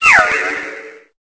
Cri de Sucroquin dans Pokémon Épée et Bouclier.